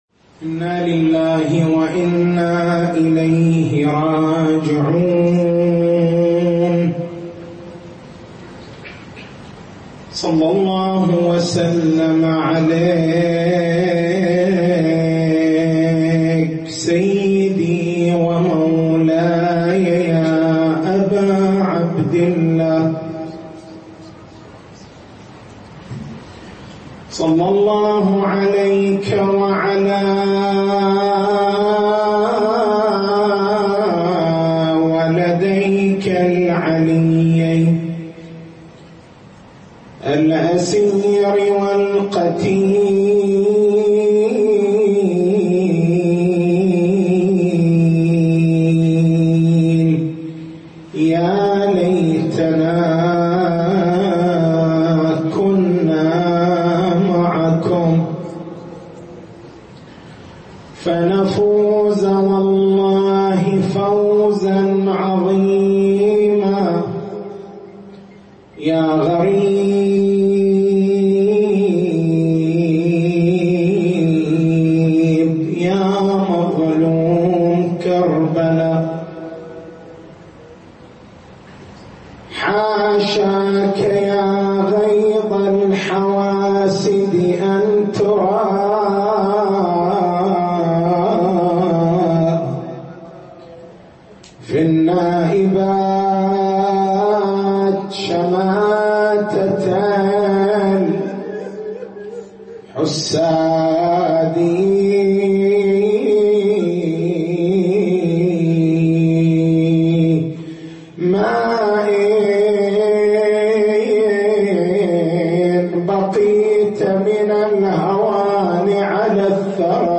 تاريخ المحاضرة: 25/01/1439 نقاط البحث: مبرّرات اتّخاذ هذا المنهج معطيات هذا المنهج وثمراته التسجيل الصوتي: اليوتيوب: شبكة الضياء > مكتبة المحاضرات > محرم الحرام > محرم الحرام 1439